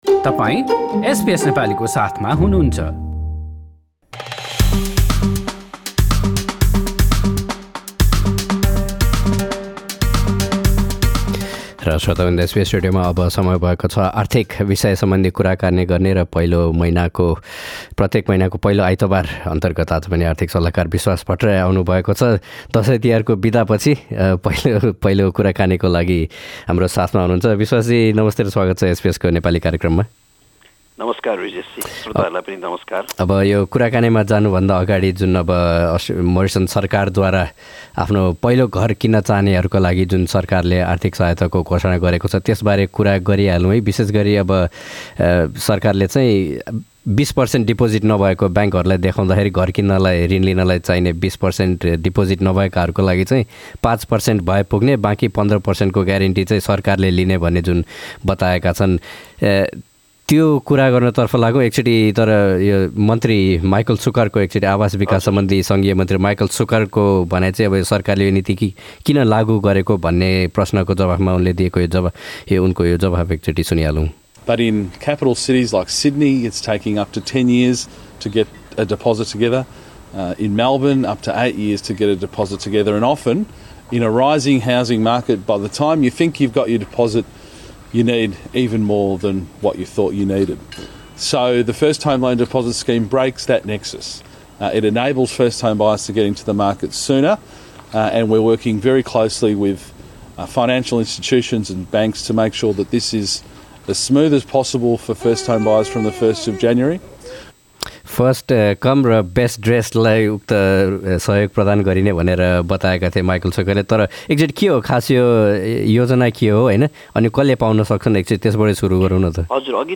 यस मासिक शृंखलामा चर्चा गरिएका अन्य विषयहरु: गए अक्टोबर महिनामा सिड्नी र मेलबर्न लगायतका सहरहरुमा घरहरुको भाउ बढेता पनि पर्थमा चाहिं किन घट्यो? अपार्टमेन्ट बन्नु अगाडी नै, नक्साको आधारमा किनिएका अपार्टमेन्टहरुको भाउ घटेपछि ऋण लिएकाहरुलाई असर परेको भन्ने भनाइ सहि हो त? यी लगायतका विषयहरु सम्बन्धि कुराकानी सुन्न माथि रहेको मिडिया प्लेयरमा क्लिक गर्नुहोस्।